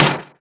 lightbulbNtrHit.ogg